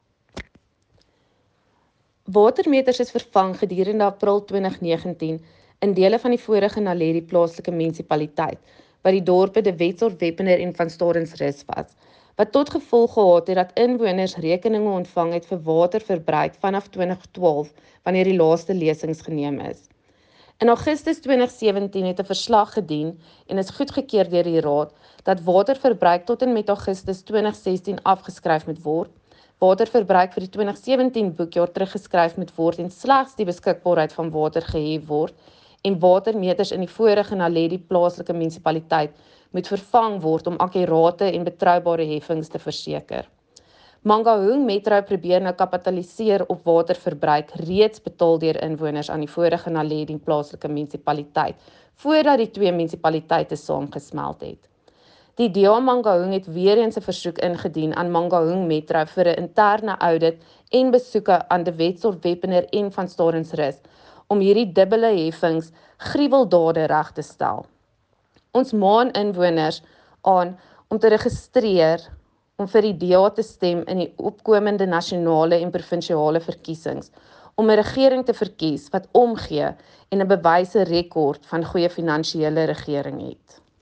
Afrikaans soundbites by Cllr Maryke Davies and Sesotho by Cllr David Masoeu.